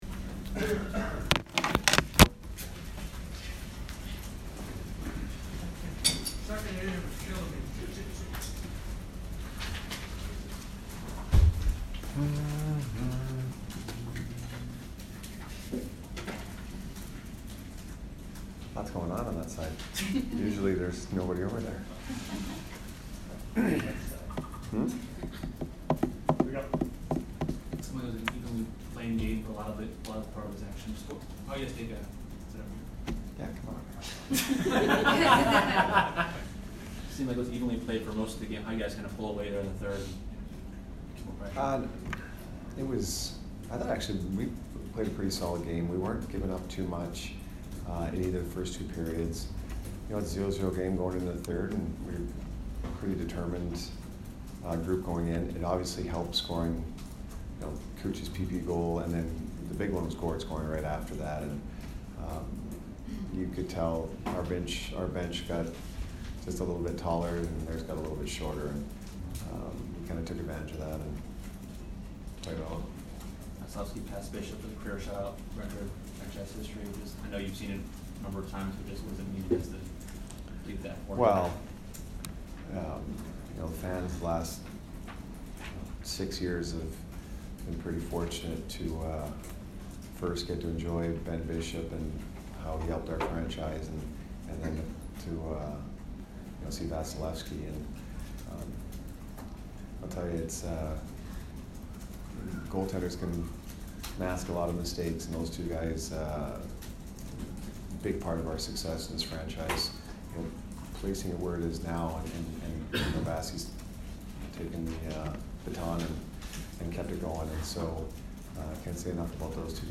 Jon Cooper post-game 2/16